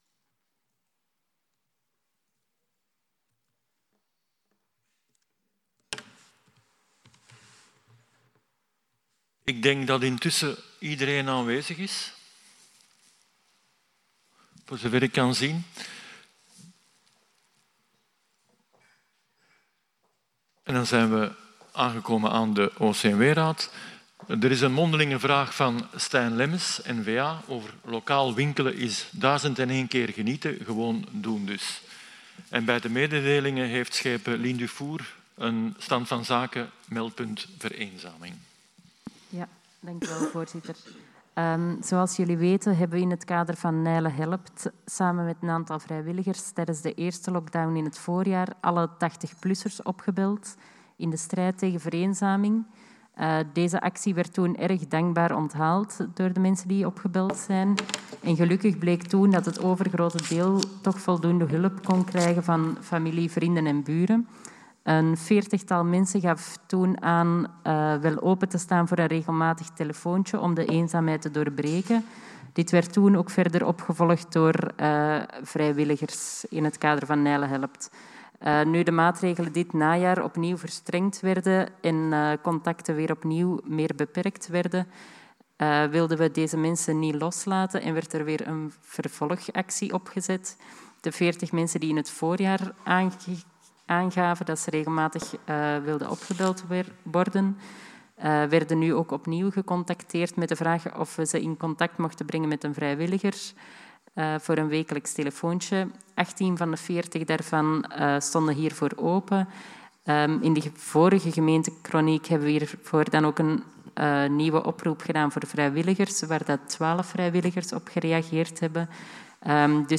Audioverslag Raad voor Maatschappelijk Welzijn van 15 december 202010,6 Mb(mp3)